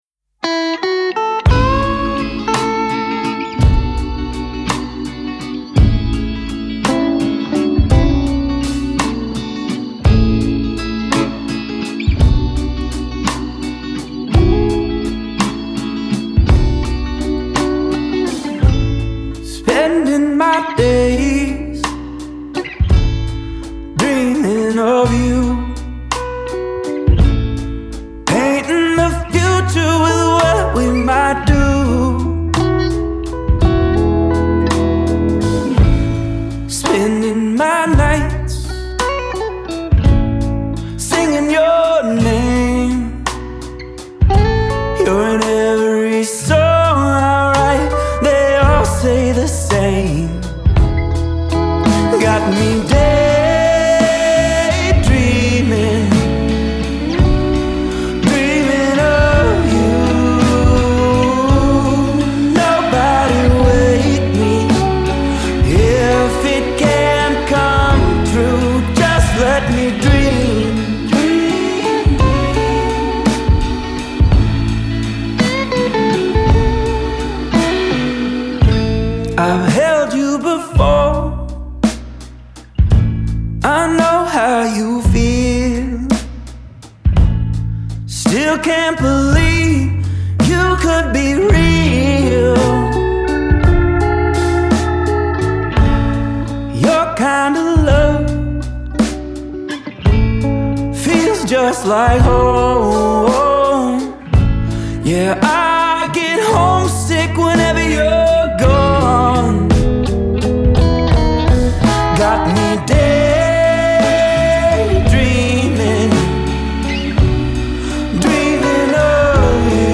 swoony long-distance love song